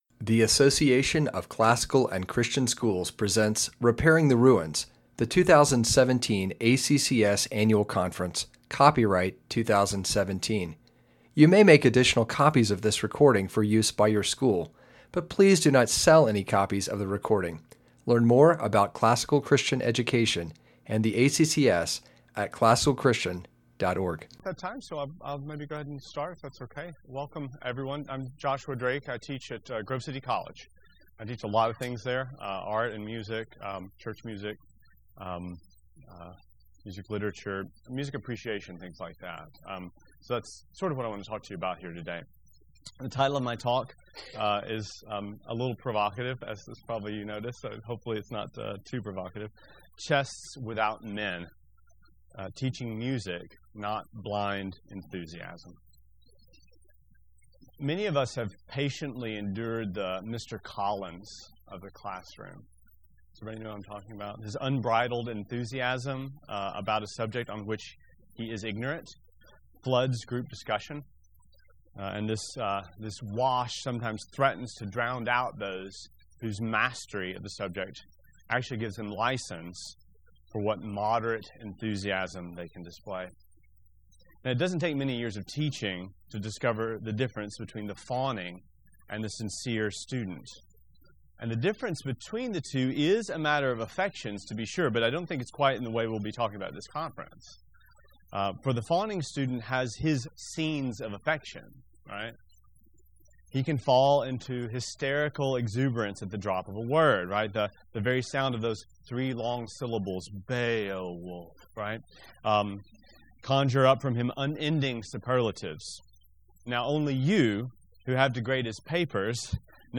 2017 Workshop Talk | 1:02:31 | All Grade Levels, Art & Music
Speaker Additional Materials The Association of Classical & Christian Schools presents Repairing the Ruins, the ACCS annual conference, copyright ACCS.